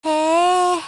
HowaType89_LOWMOOD_JP.mp3